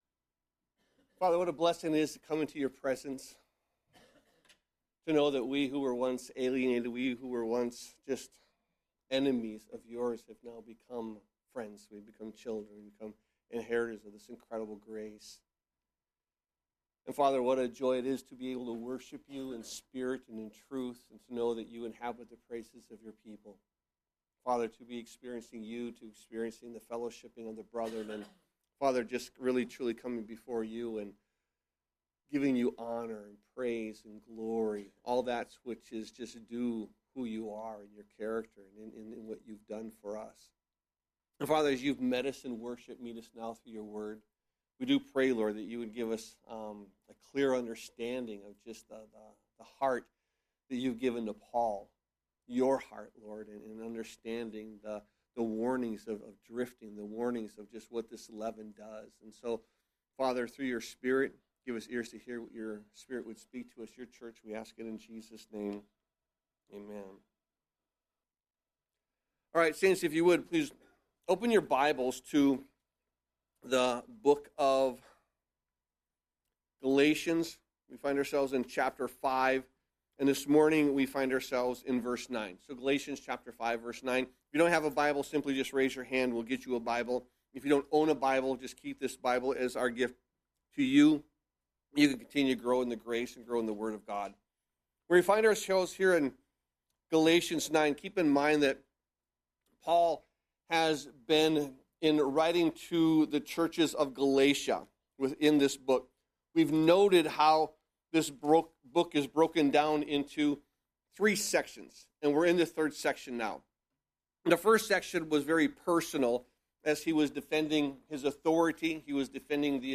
Sermons | Calvary Chapel Milwaukee